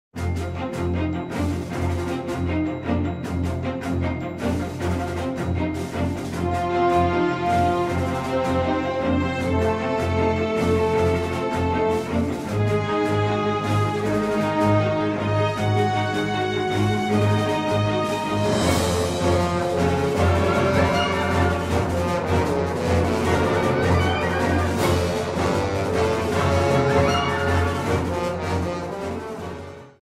Trimmed to 30 seconds, with a fade out effect